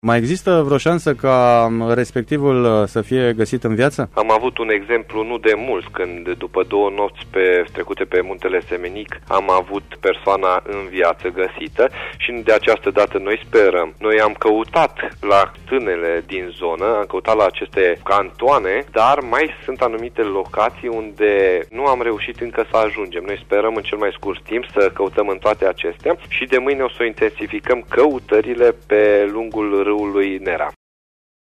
Acesta a declarat în emisiunea „Oameni MAI aproape de tine” că nu sunt excluse şansele ca persoana respectivă să fie găsită în viaţă.